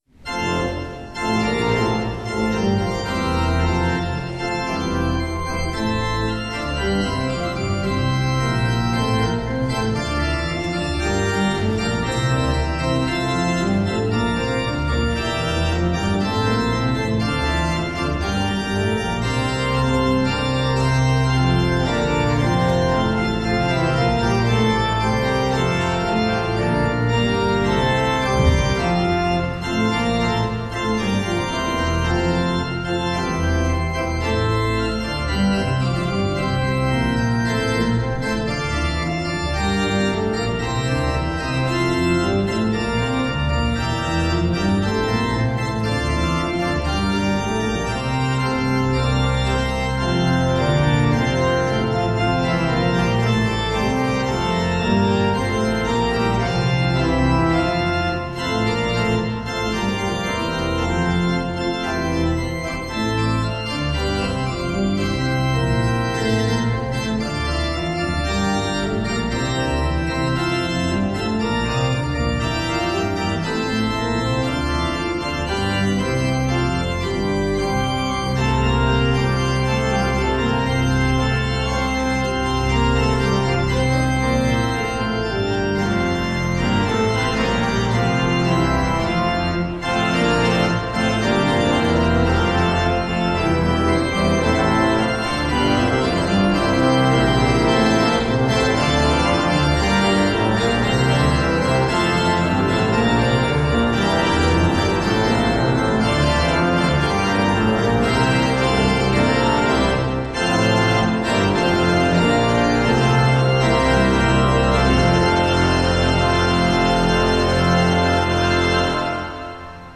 Hear the Bible Study from St. Paul's Lutheran Church in Des Peres, MO, from April 19, 2026.